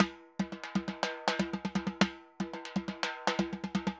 Timba_Merengue 120_1.wav